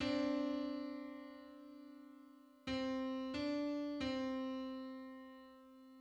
Just: 285/256 = 185.78 cents.
Public domain Public domain false false This media depicts a musical interval outside of a specific musical context.
Two-hundred-eighty-fifth_harmonic_on_C.mid.mp3